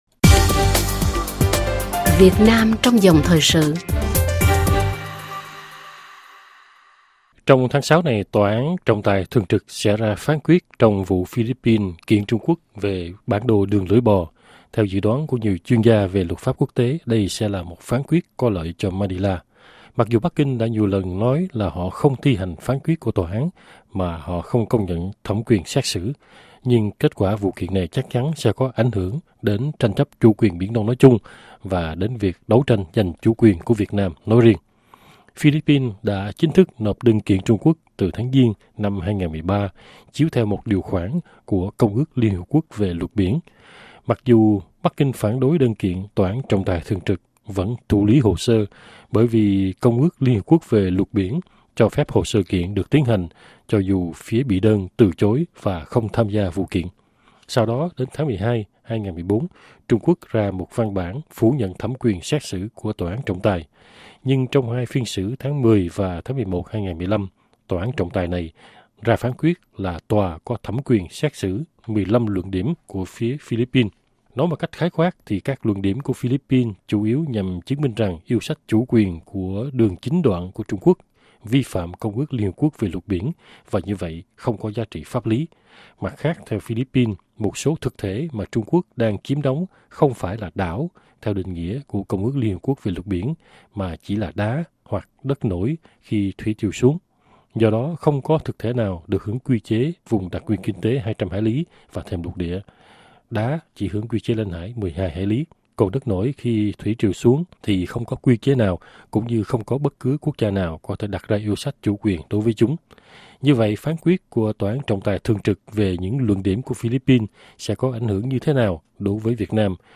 trả lời phỏng vấn RFI từ Boston